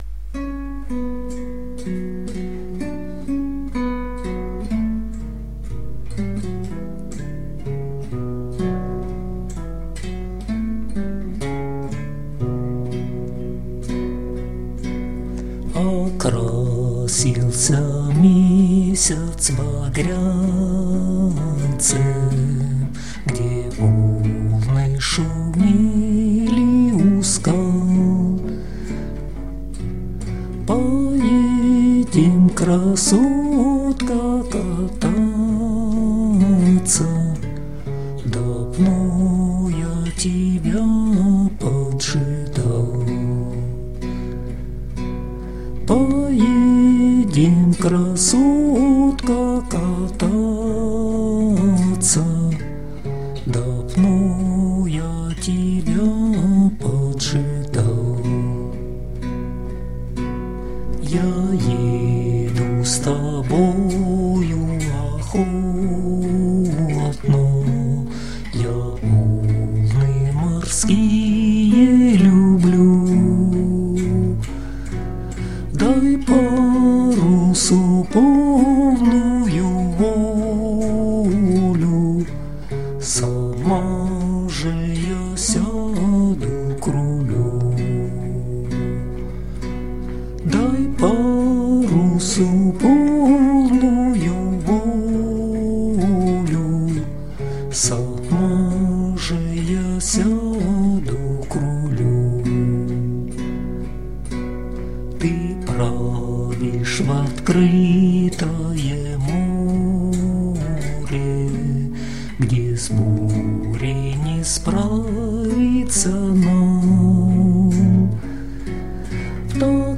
../icons/oleandr.jpg   Русская народная песня